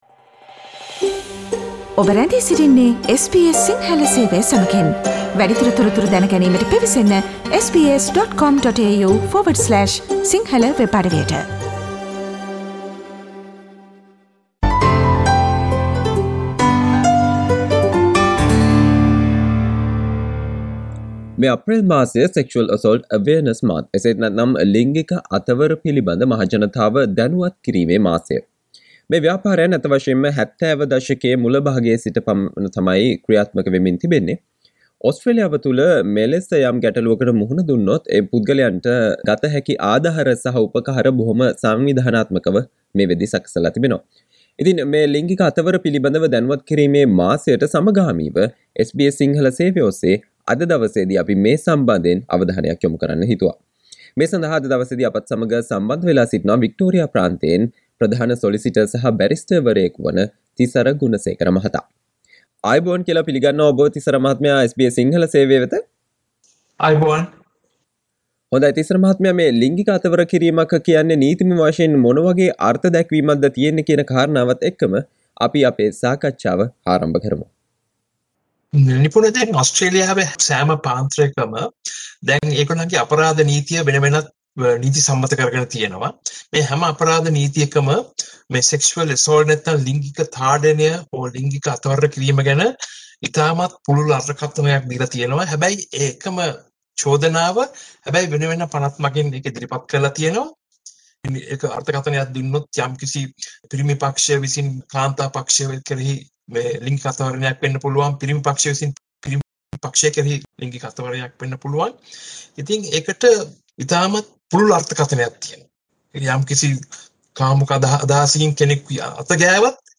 Listen to the discussion on what is sexual harassment and the help available in Australia